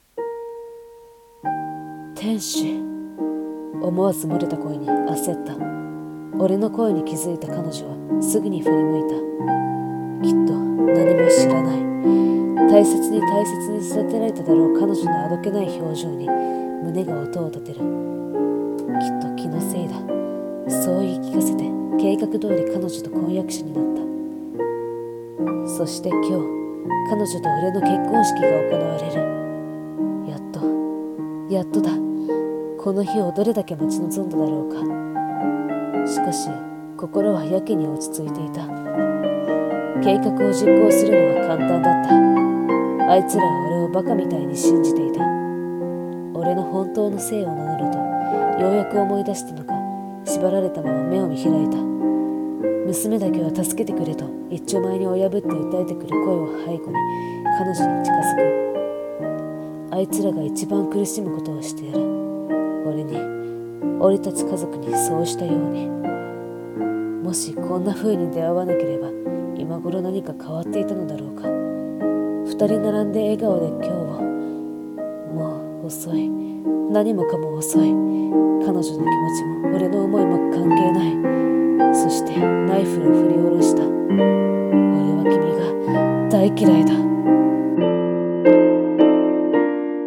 【声劇】復讐王子